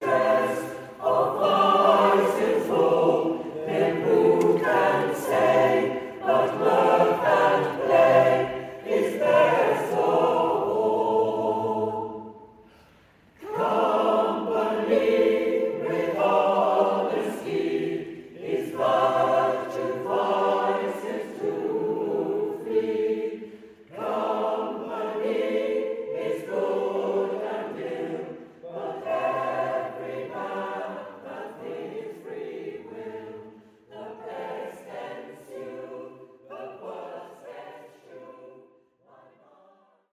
The following audio recordings are snippets from previous concerts to give you a taste of our repertoire